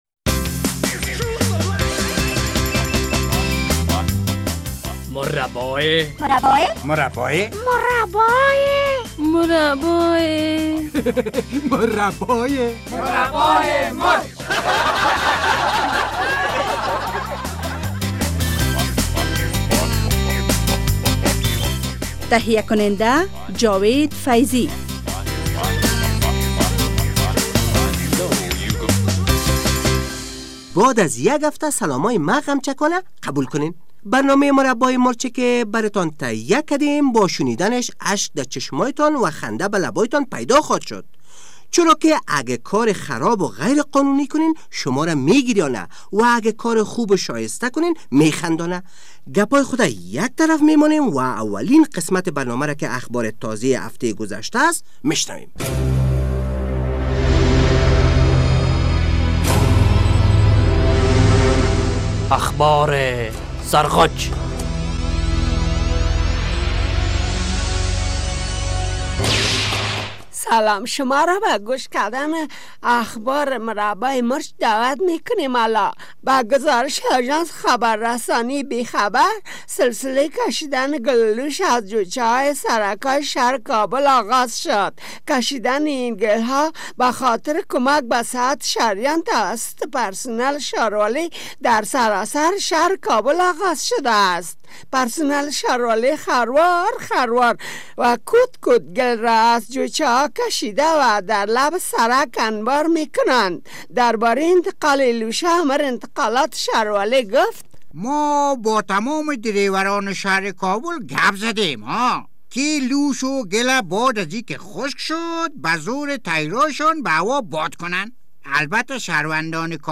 گل ولوش جویچه ها و آهنگ قطغنی عاشقان بی سرحد